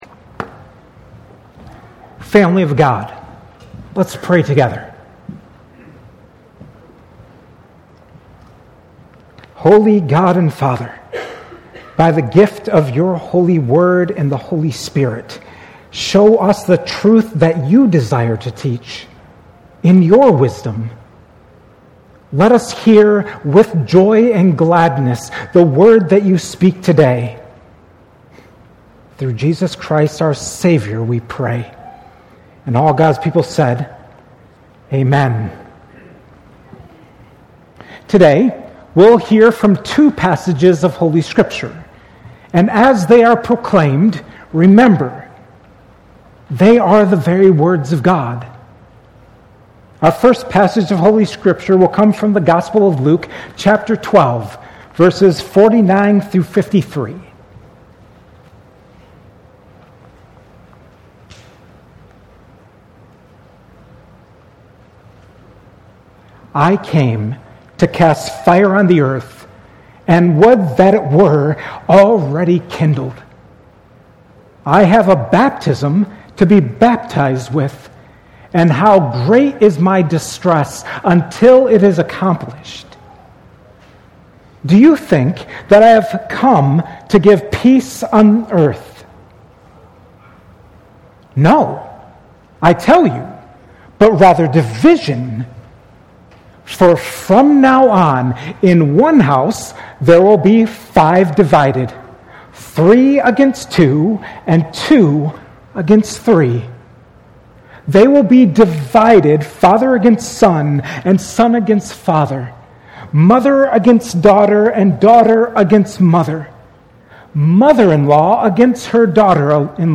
at Cornerstone Church on August 17, 2025.